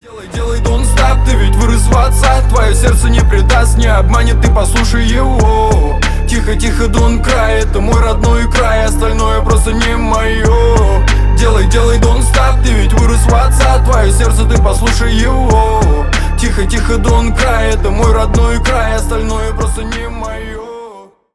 рэп , хип хоп